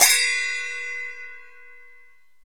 Index of /90_sSampleCDs/Roland - Rhythm Section/PRC_Asian 2/PRC_Gongs
PRC SPLASH08.wav